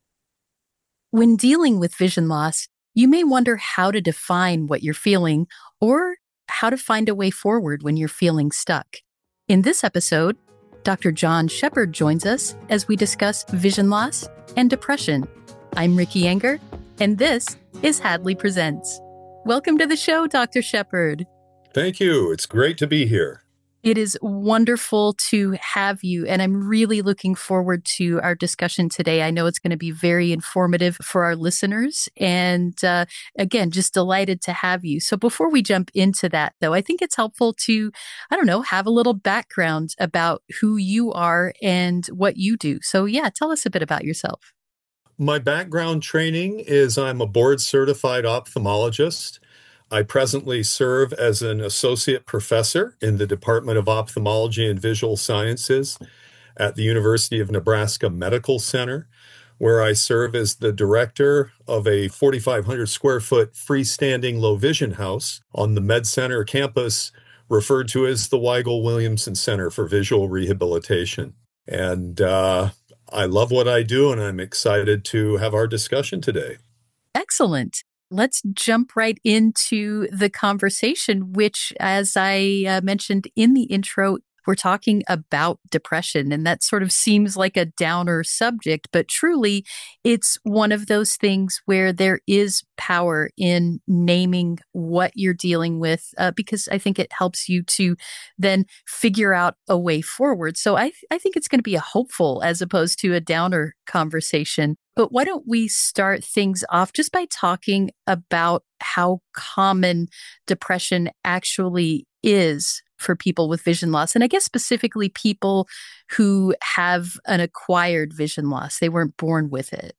A Conversation with the Experts